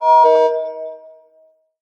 paEndReverb.ogg